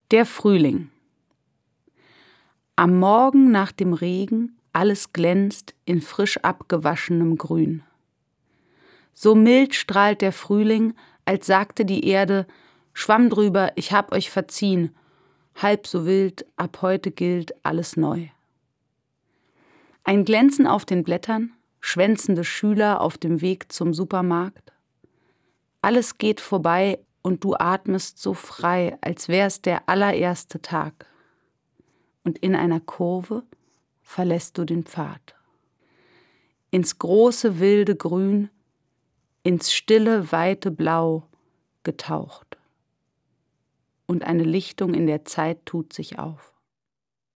Das radio3-Gedicht der Woche: Dichter von heute lesen radiophone Lyrik.